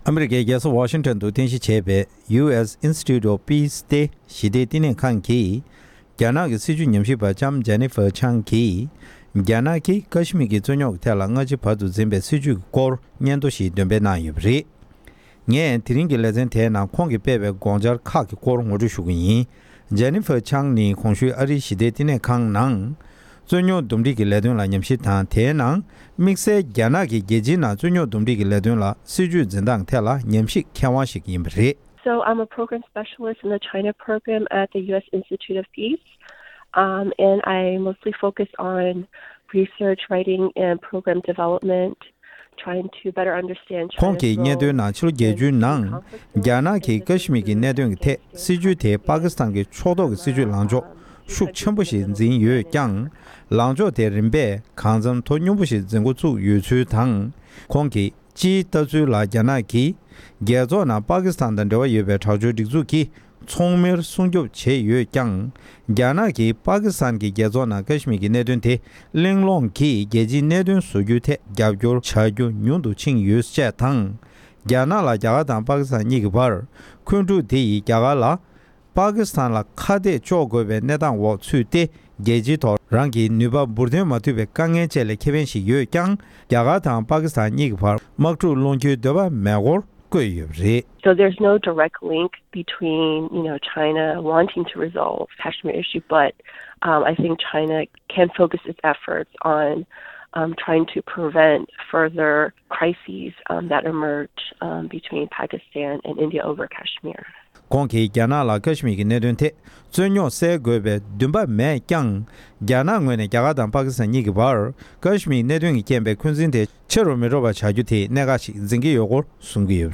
རྒྱ་ནག་གིས་ཀཤ་མིར་འཛིན་པའི་སྲིད་བྱུས་ཐད་སྙན་ཐོ།
སྒྲ་ལྡན་གསར་འགྱུར། སྒྲ་ཕབ་ལེན།